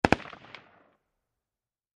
Rugar Mini 14 Machine Gun Burst From Distant Point of View, X5